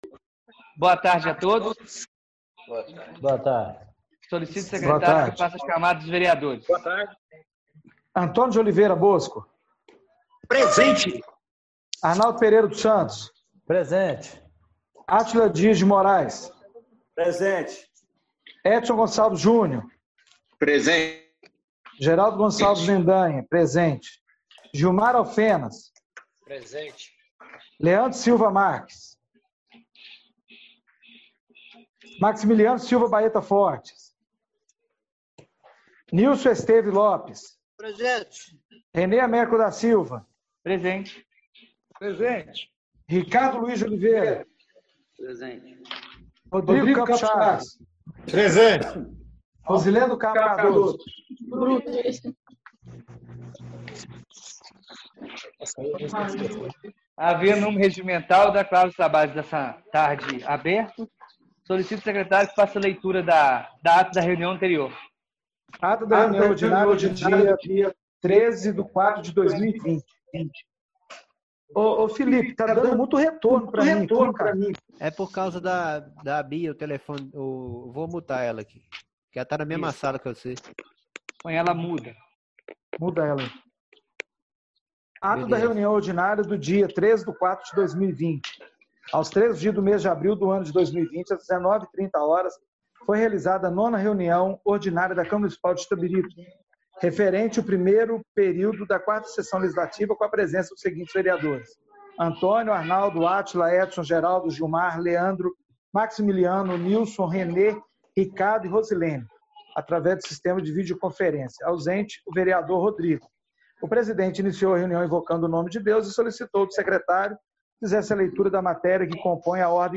Reunião Extraordinária do dia 15/04/2020